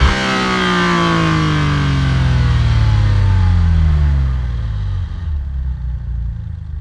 rr3-assets/files/.depot/audio/Vehicles/f6_02/f6_02_decel.wav
f6_02_decel.wav